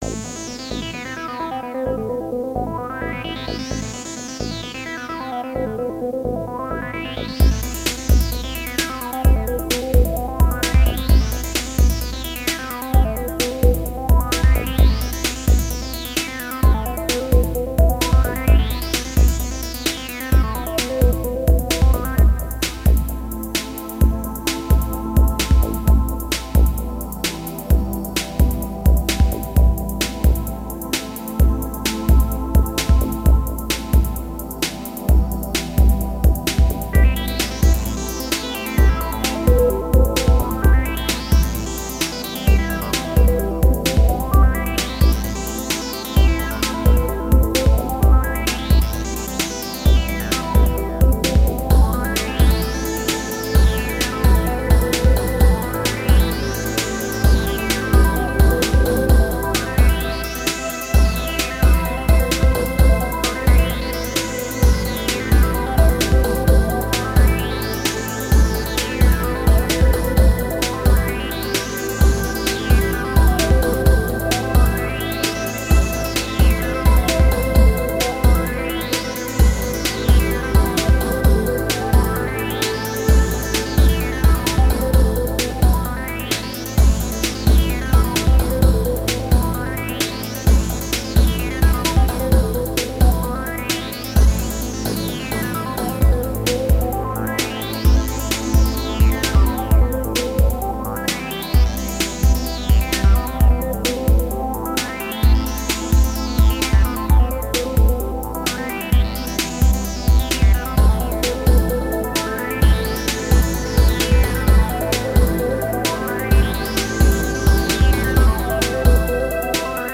Sublime and ethereal electronic music.